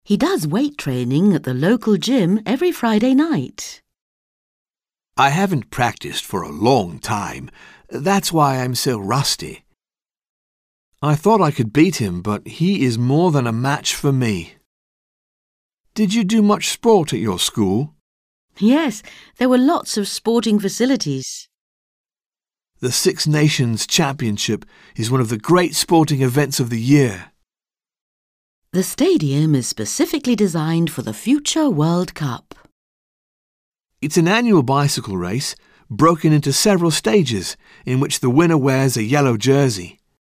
Un peu de conversation - Faire du sport